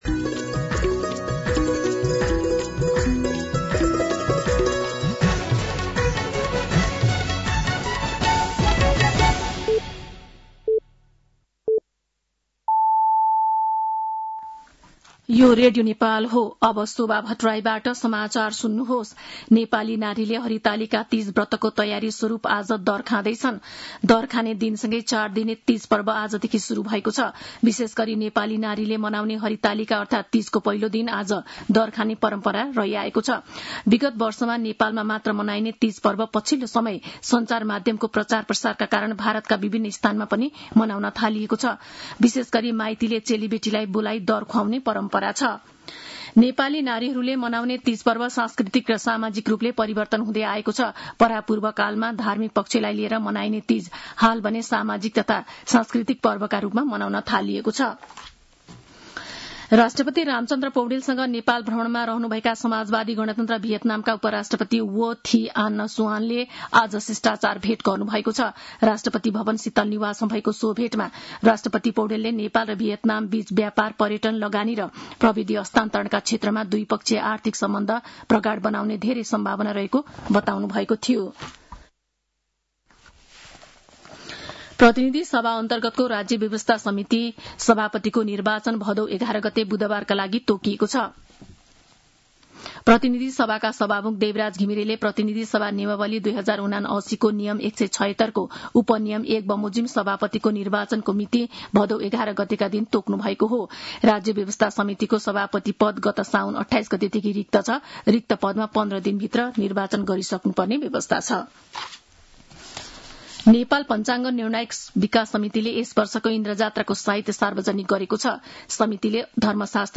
साँझ ५ बजेको नेपाली समाचार : ९ भदौ , २०८२
5.-pm-nepali-news-1-9.mp3